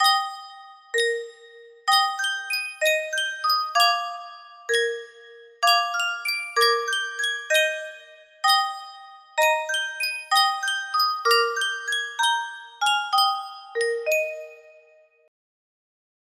Sankyo Music Box - Oh Dear What Can the Matter Be AXE music box melody
Full range 60